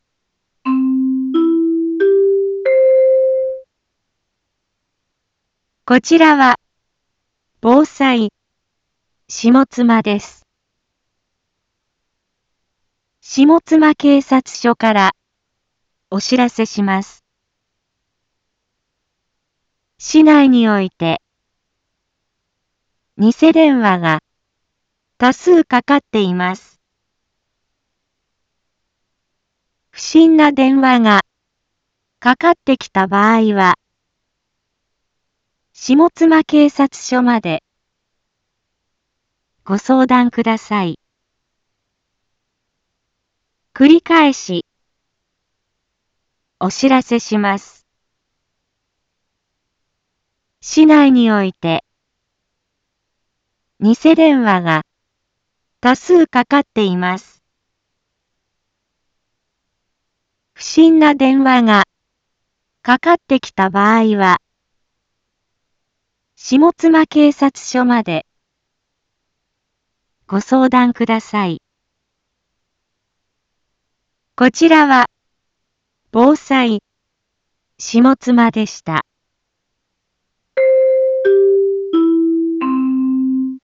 一般放送情報
Back Home 一般放送情報 音声放送 再生 一般放送情報 登録日時：2021-02-04 12:31:28 タイトル：ニセ電話詐欺にご注意を インフォメーション：こちらはぼうさいしもつまです。